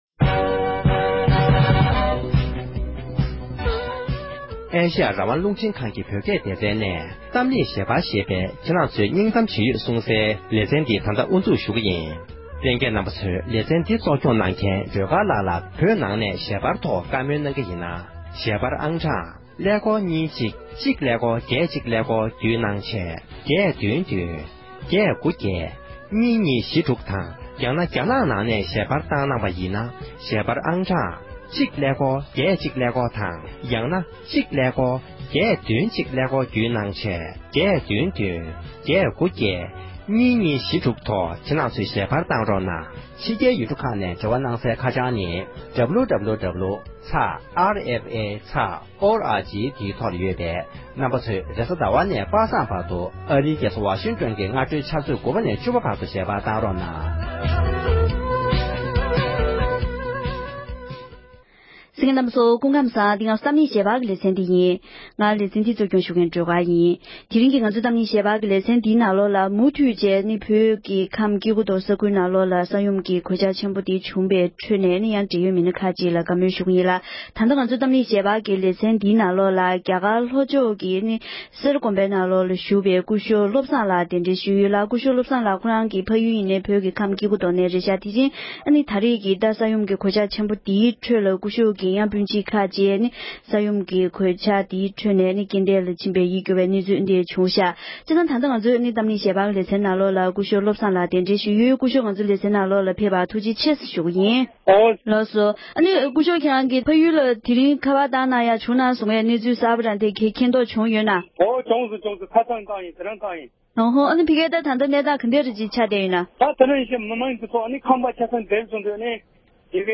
ཡུལ་ཤུལ་གྱི་ས་འགུལ་གནོད་འཚེའི་ཁྲོད་འདས་གྲོངས་ལ་སོང་བའི་ནང་མིར་བཀའ་མོལ་ཞུས་པ།